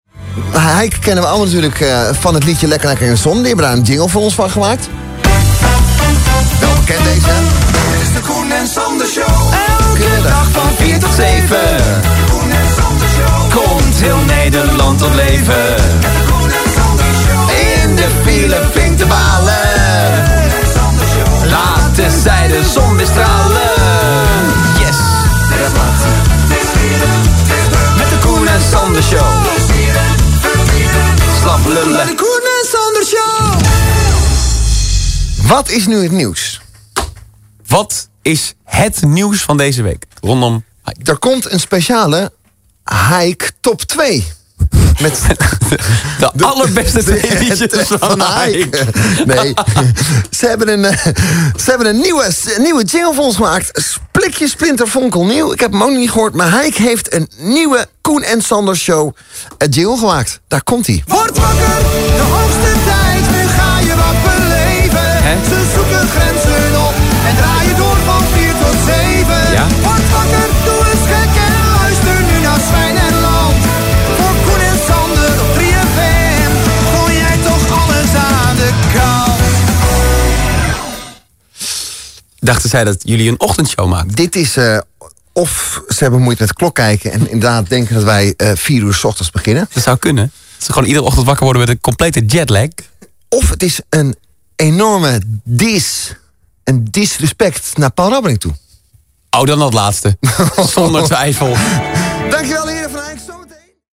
doen veel met eigen programmajingles.